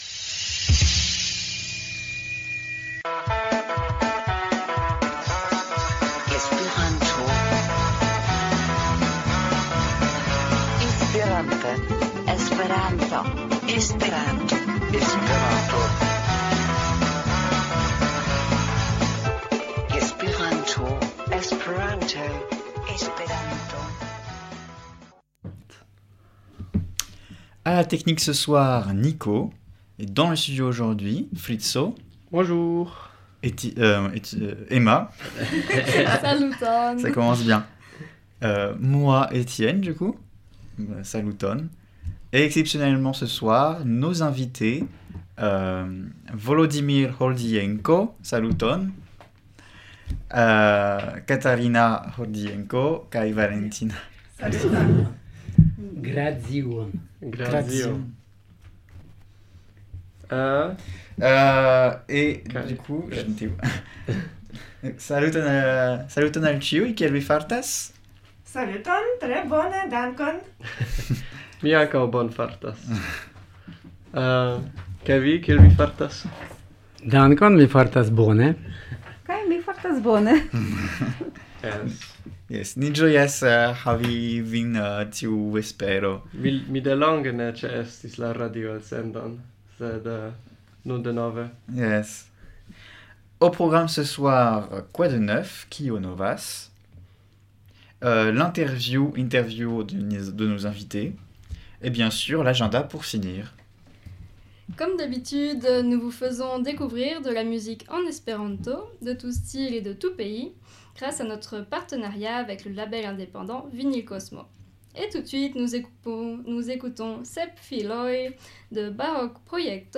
Esperanto-Magazino est l’émission hebdomadaire sur l’espéranto à Toulouse (avec de la musique en espéranto, des annonces, des petits reportages, et des chroniques… ).